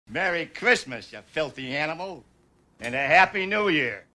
Cell Phone Notification Sounds in MP3 Format
Merry Christmas Ya Filthy Animal (No Gunshots) (Home Alone)